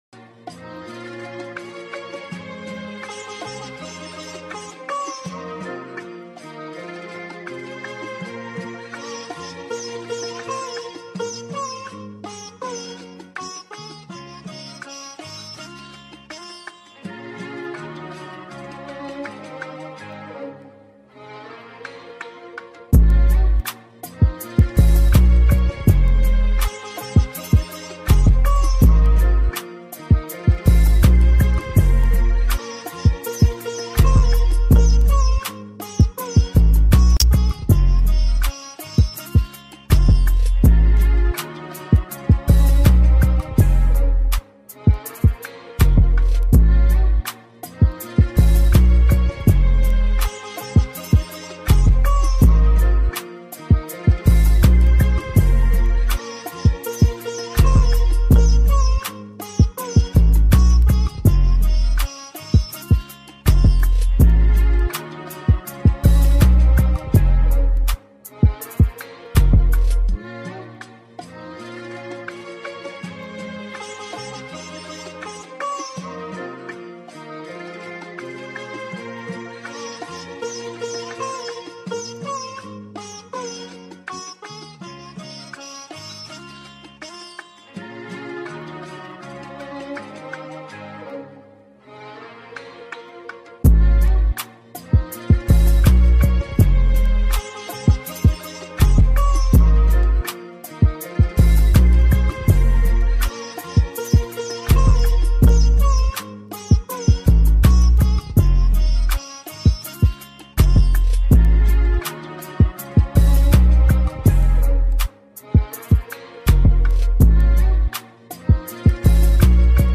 EDM Remix New Song